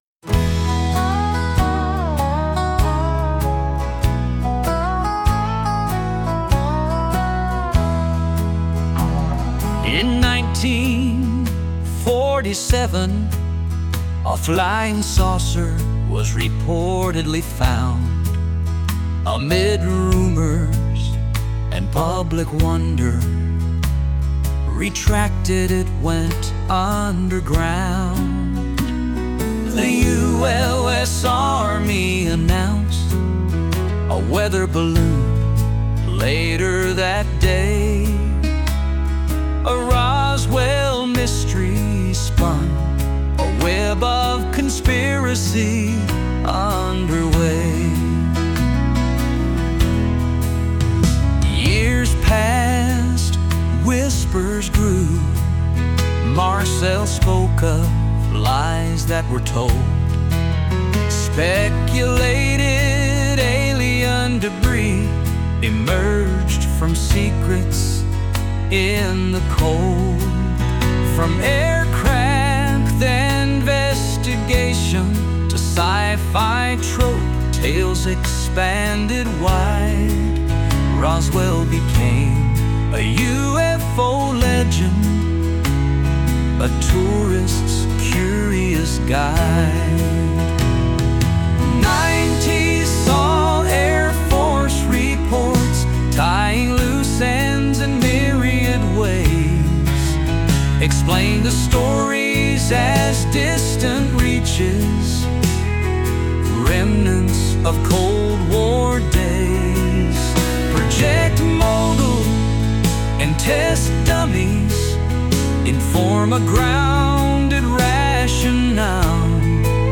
Country song – based on the Roswell Incident Wikipedia page